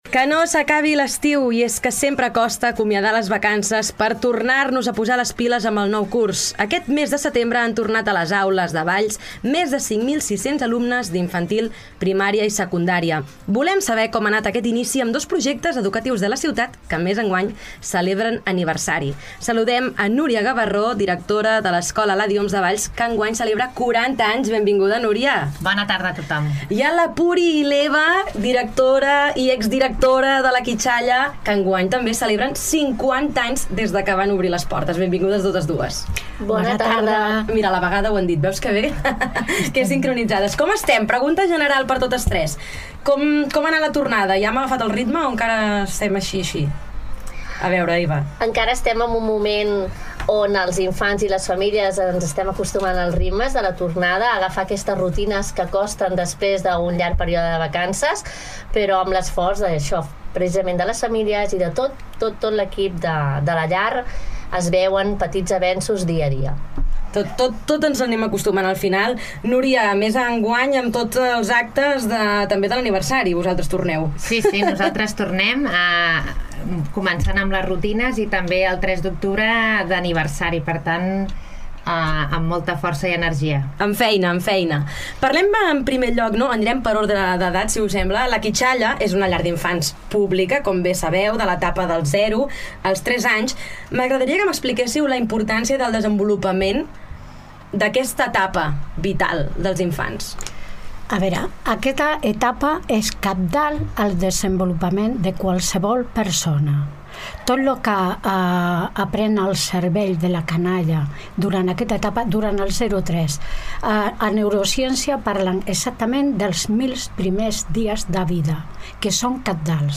Nova entrevista a Ràdio Ciutat de Valls. Fa un mes que ha començat el nou curs escolar i el final del 2025 s’emmarca en el 50è aniversari de la llar d’infants La Quitxalla i el 40è aniversari de l’Escola Eladi Homs.